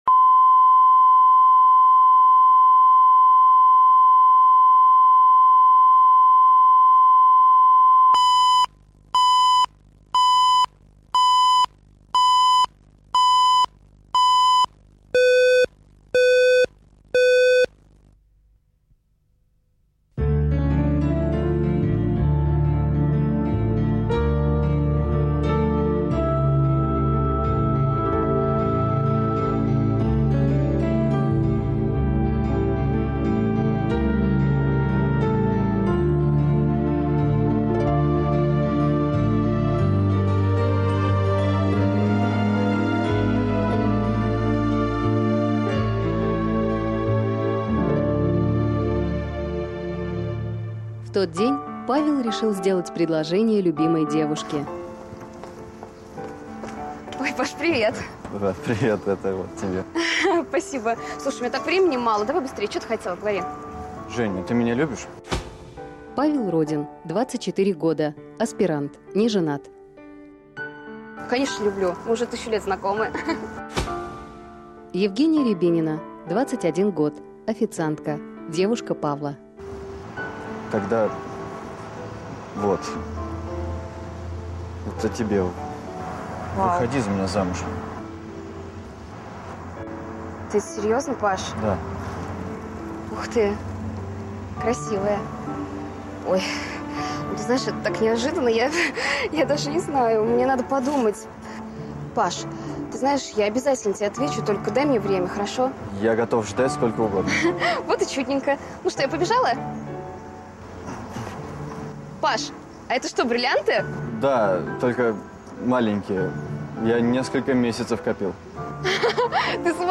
Аудиокнига Запасной игрок | Библиотека аудиокниг